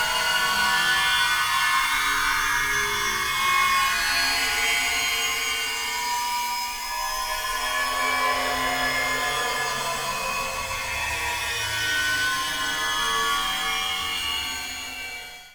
單聲道 (1ch)
乐器类